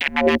Rave Bass 1.wav